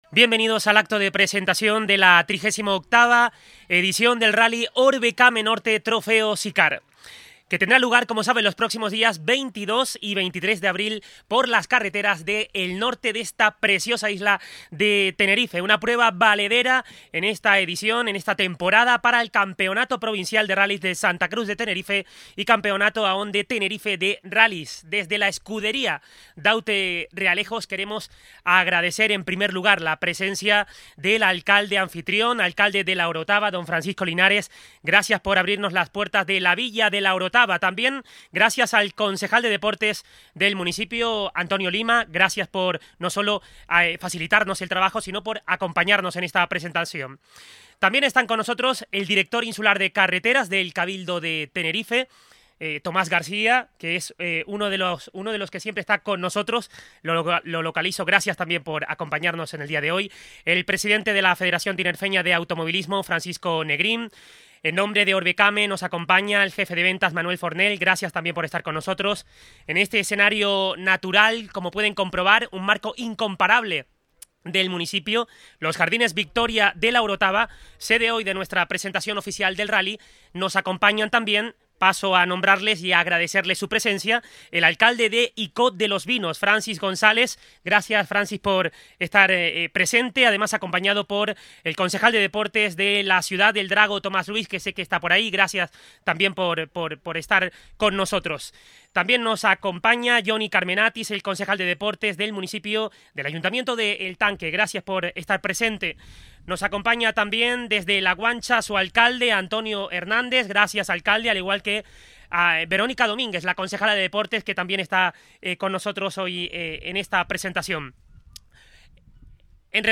La Orotava. Acto de Presentación del 38º Rallye Overcame Norte.